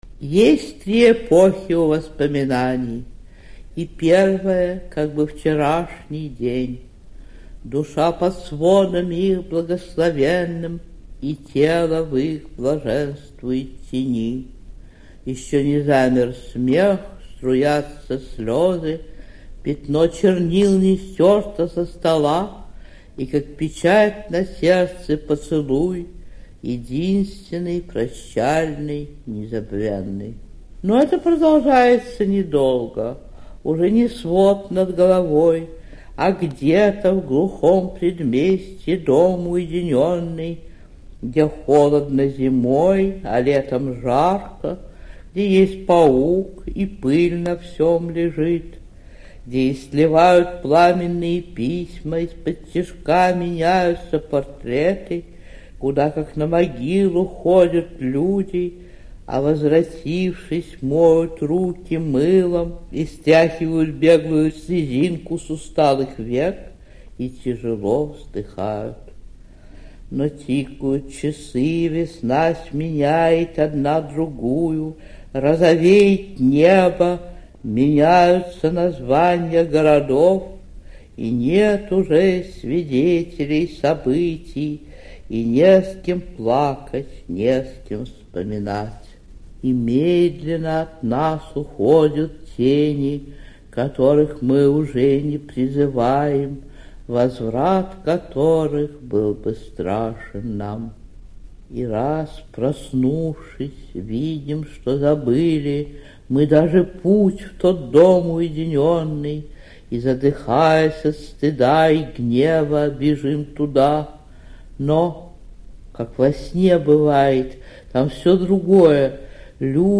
4. «Анна Ахматова – Северные элегии – Шестая – Есть три эпохи у воспоминаний (читает автор)» /